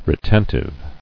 [re·ten·tive]